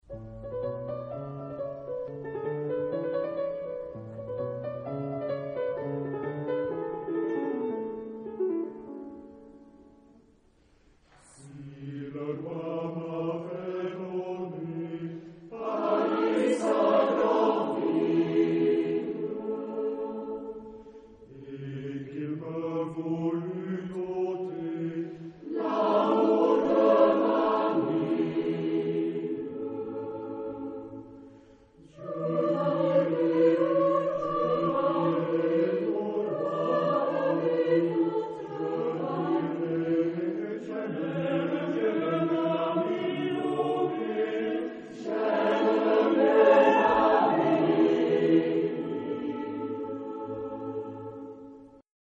SATB (4 voix mixtes) ; Partition complète.
Chanson. Folklore.